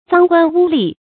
贓官污吏 注音： ㄗㄤ ㄍㄨㄢ ㄨ ㄌㄧˋ 讀音讀法： 意思解釋： 猶貪官污吏。